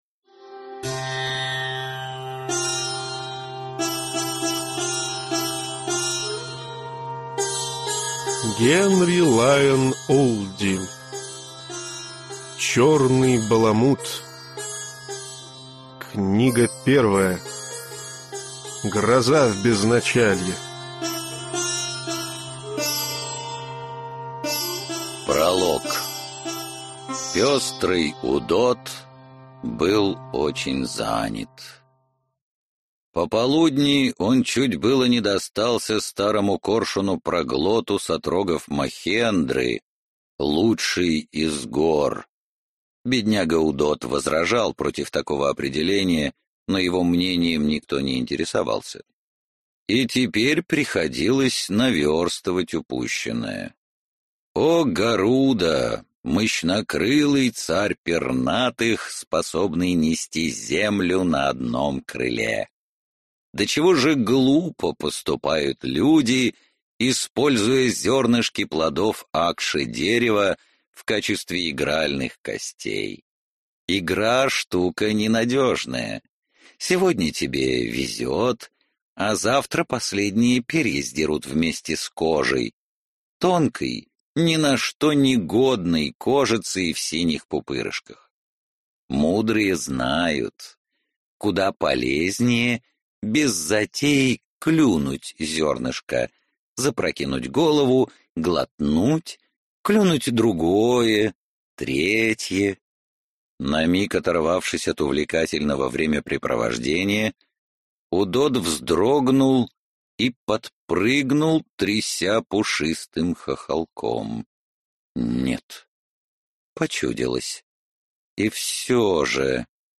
Аудиокнига Гроза в Безначалье | Библиотека аудиокниг